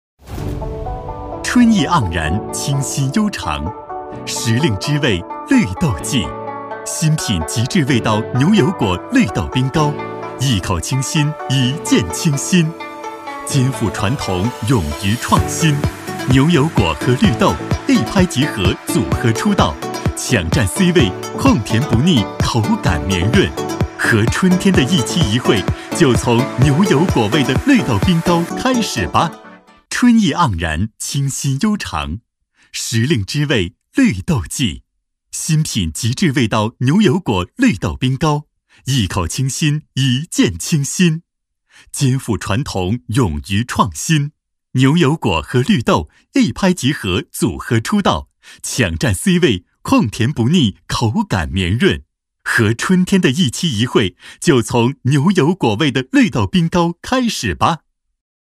男14-MG风格《冰糕》-活泼轻快
男14-透亮故事感 轻松活泼
男14-MG风格《冰糕》-活泼轻快.mp3